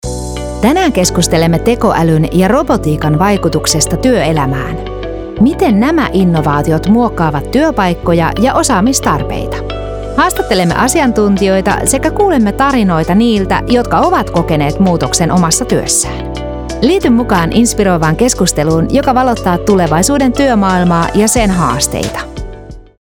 I record in a professional studio environment with professional recording equipment.
ConversationalTrustworthyEnergeticNeutralExpressive